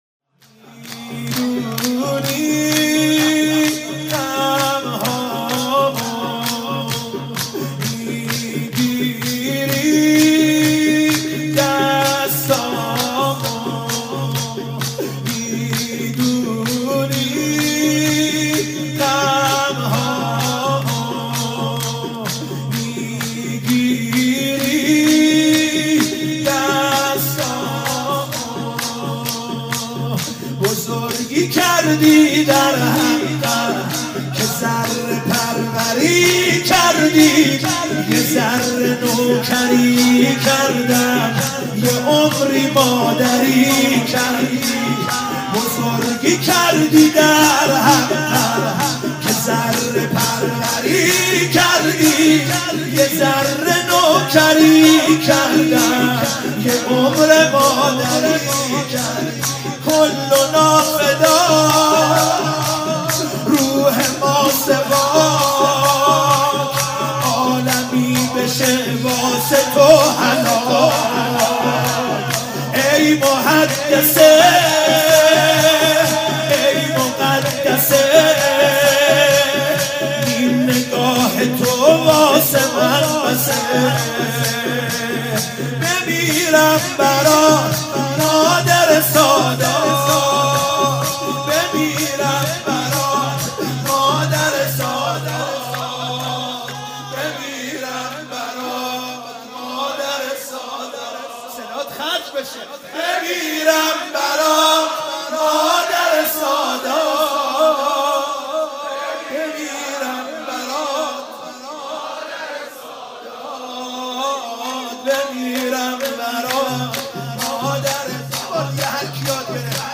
فاطمیه 96 - هیئت یازهرا(س)قم - شور - بزرگی کردی در حقم
فاطمیه